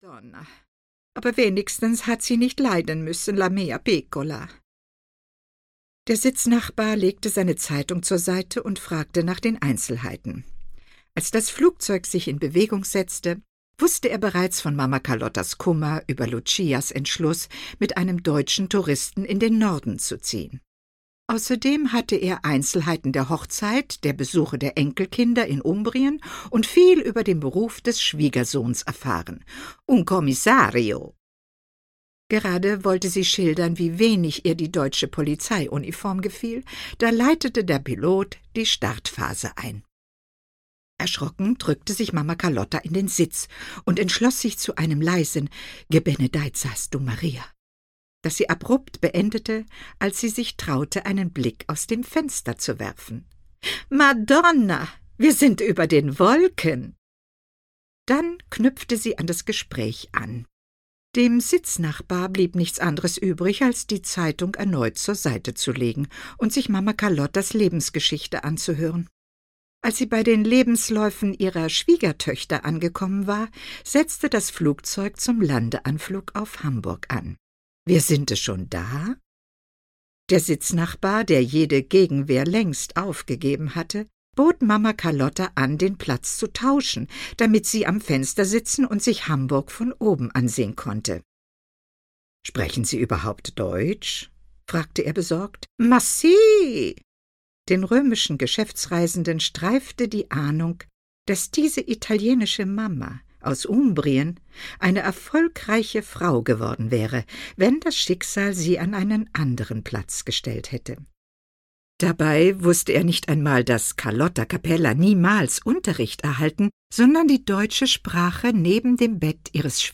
Die Tote am Watt (Mamma Carlotta 1) - Gisa Pauly - Hörbuch